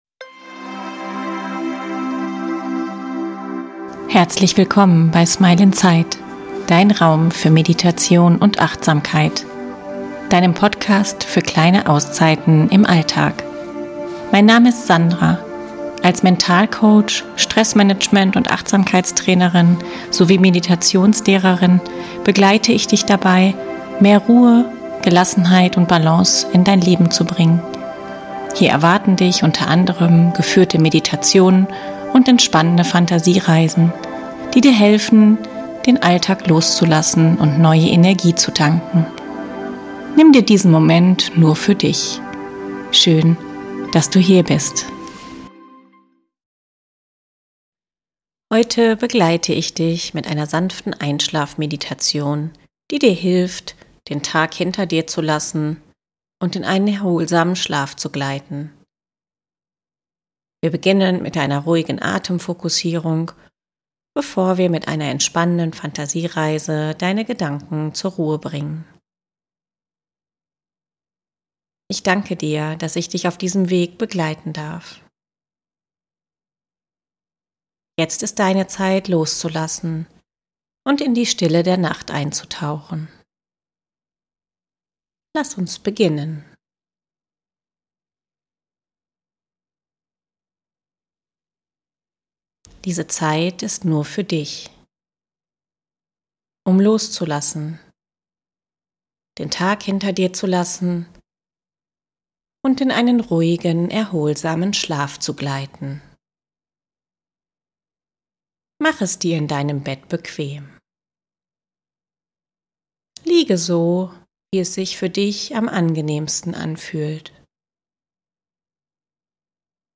Heute erwartet dich eine sanfte Einschlafmeditation, die dich dabei unterstützt, den Tag loszulassen und in einen erholsamen Schlaf zu gleiten. Wir beginnen mit einer entspannten Atemfokussierung, bevor wir mit einer beruhigenden Fantasiereise deine Gedanken zur Ruhe bringen. Du brauchst nichts weiter zu tun – nur zuzuhören und dich von meiner Stimme sanft in den Schlaf begleiten zu lassen.